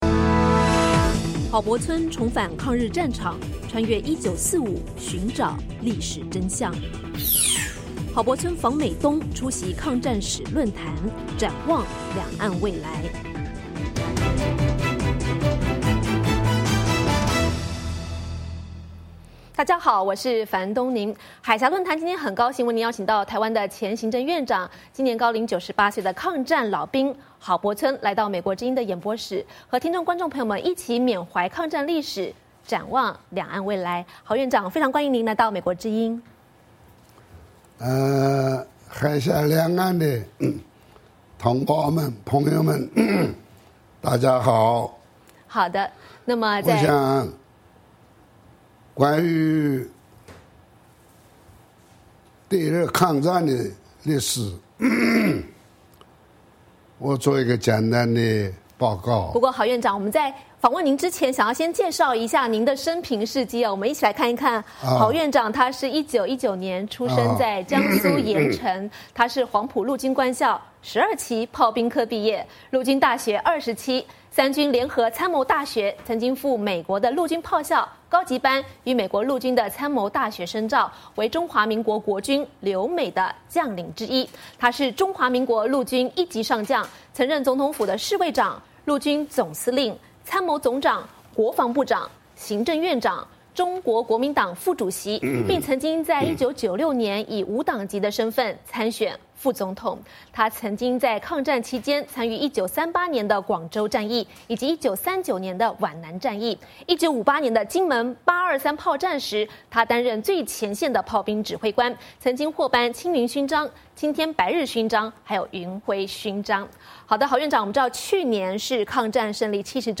海峡论谈:专访郝柏村 缅怀抗战历史
我们今天很高兴为您邀请到台湾前行政院长，抗战老兵郝柏村作客海峡论谈，畅谈抗战历史与两岸未来。